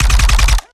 Added more sound effects.
GUNAuto_RPU1 B Burst_03_SFRMS_SCIWPNS.wav